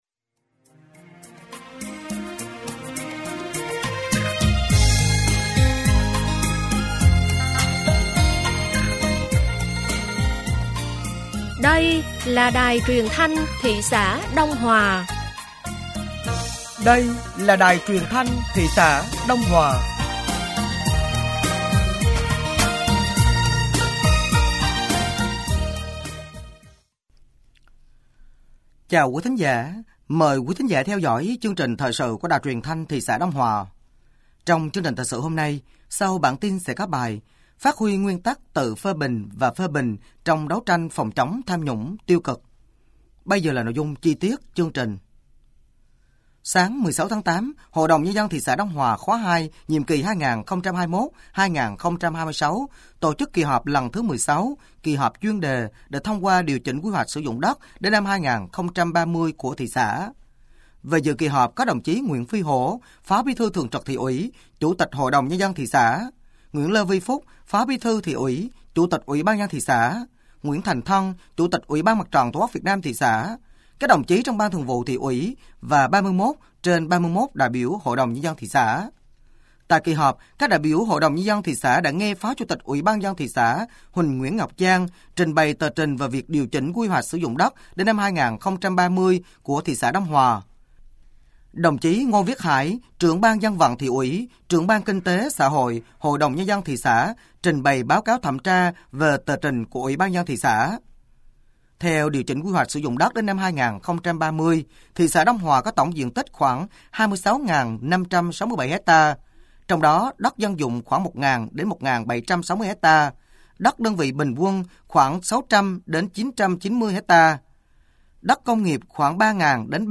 Thời sự tối ngày 16 và sáng ngày 17 tháng 8 năm 2024